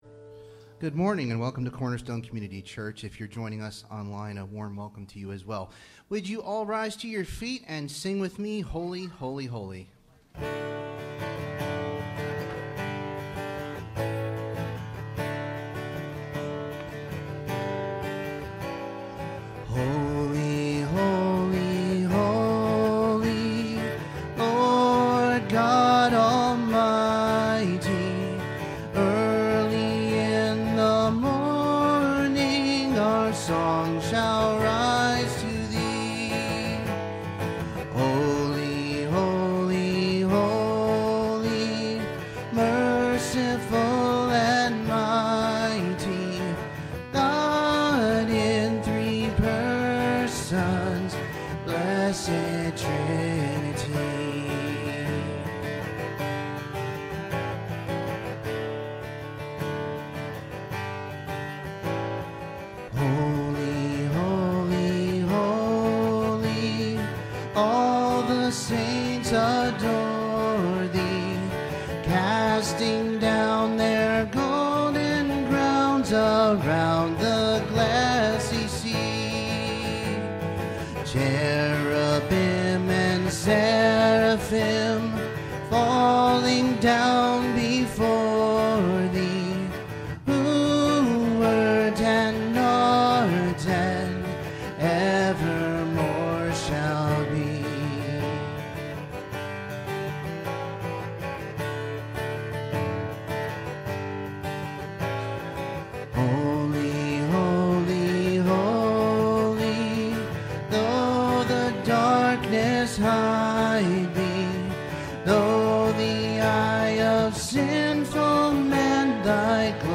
Sermon Downloads
Service Type: Sunday Morning